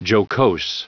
Prononciation du mot jocose en anglais (fichier audio)
Prononciation du mot : jocose